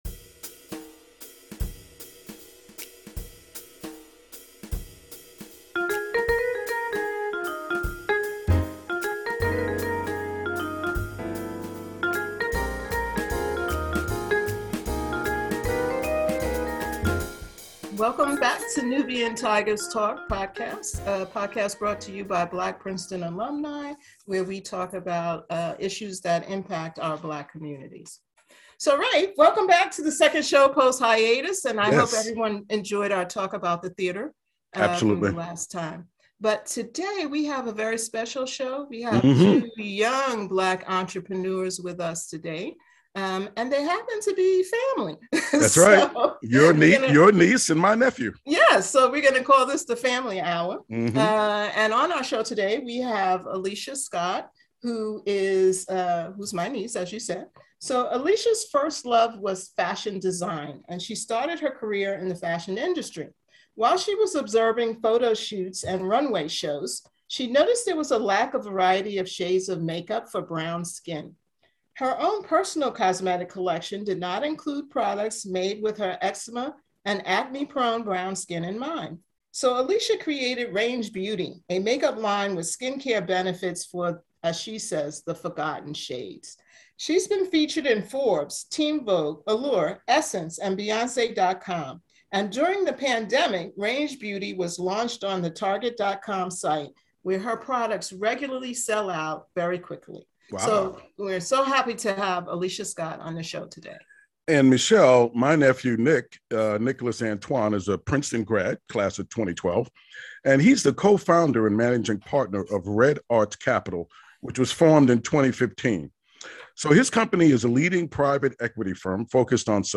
They discuss their own journeys in story telling.